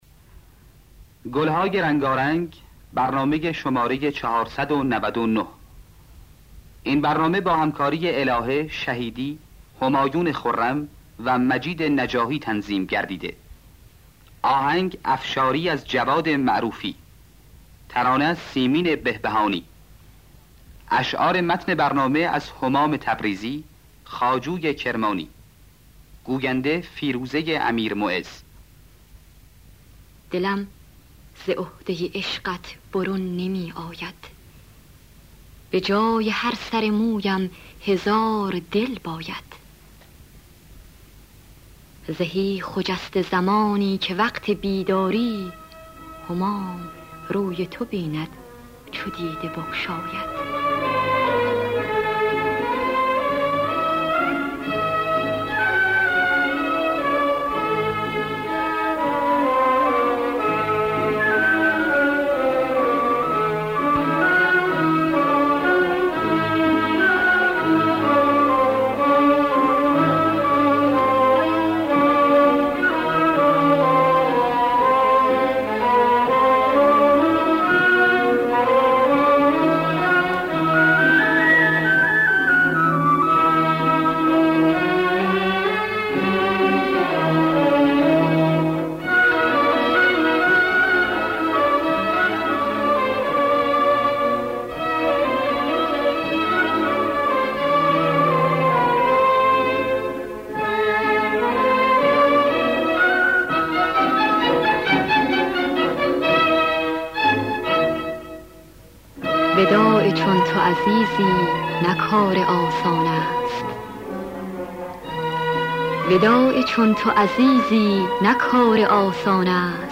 دانلود گلهای رنگارنگ ۴۹۹ با صدای الهه، عبدالوهاب شهیدی در دستگاه افشاری.